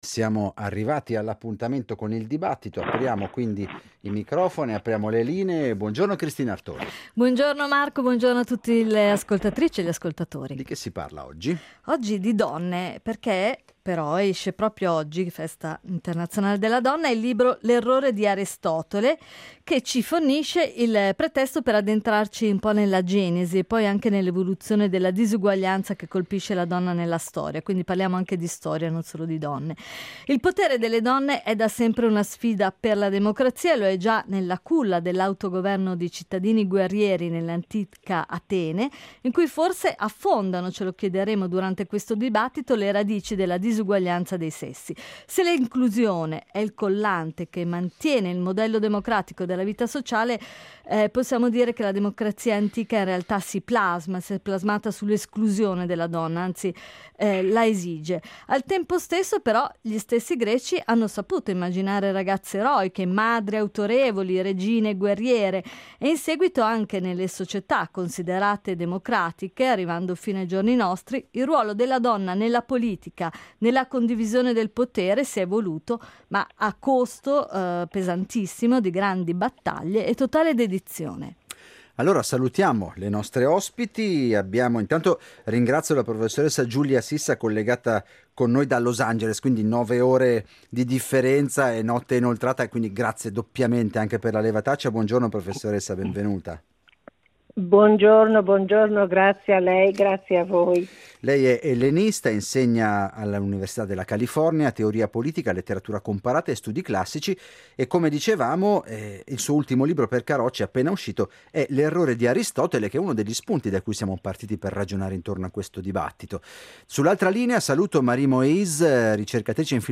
Ad Alphaville, mercoledi’ 8 marzo, dedichiamo il dibattito alla genesi dell’esclusione della donna anche nelle società considerate democratiche.